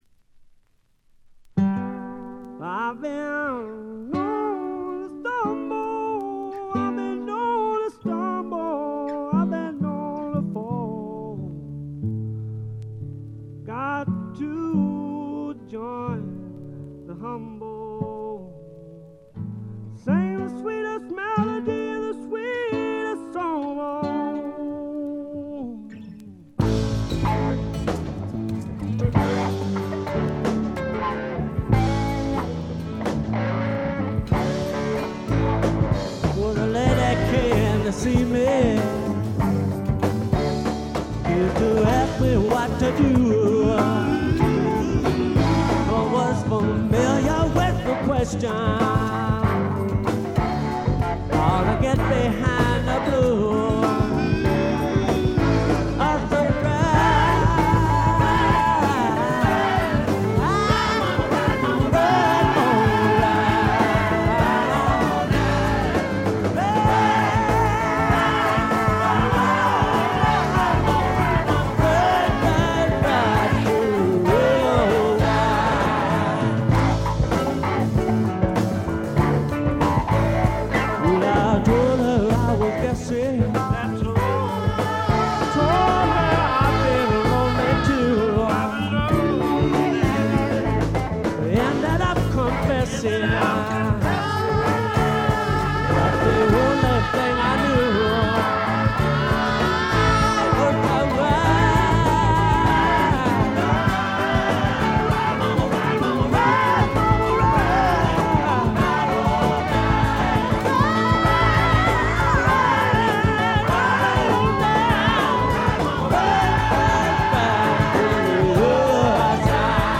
わずかなノイズ感のみ。
泣けるバラードからリズムナンバーまで、ゴスペル風味にあふれたスワンプロック。
試聴曲は現品からの取り込み音源です。